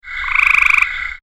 Gray Treefrog - Hyla versicolor
One call
hylaversicolorshort.mp3